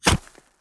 ConcreteHit01.wav